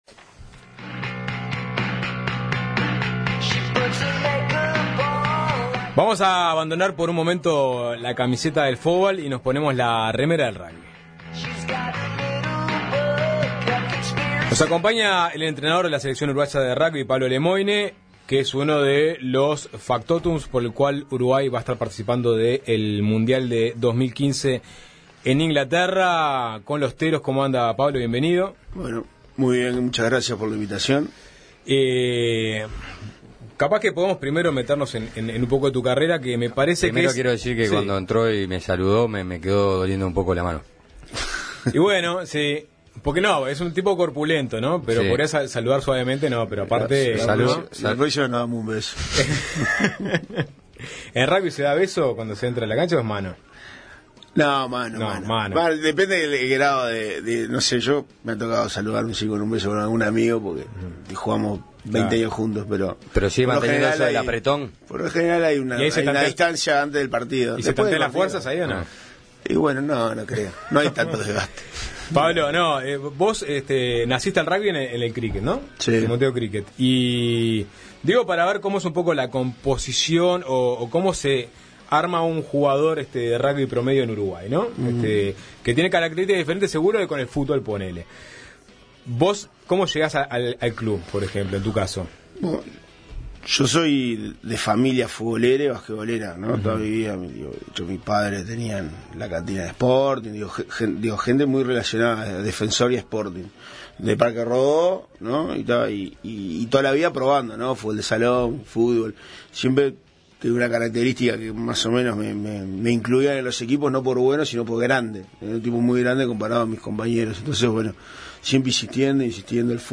Tras haber clasificado por tercera vez a un mundial, luego de vencer 36-27 a Rusia, nos visita el entrenador de la Selección Uruguaya de Rugby.